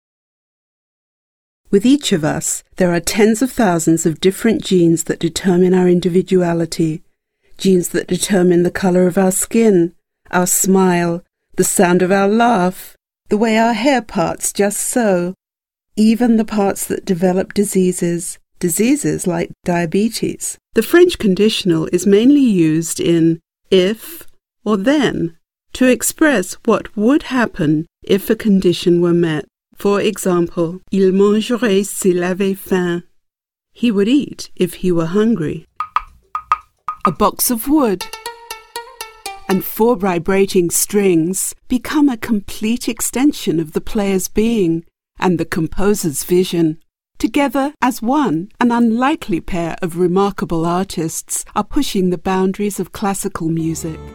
Voice Artist living in Los Angeles working in British and Global Transatlantic-Mid-Atlantic English
Sprechprobe: eLearning (Muttersprache):